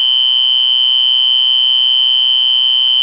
LUZ FIJA - SONIDO CONTINUO
Sonido continuo
424-425- continuo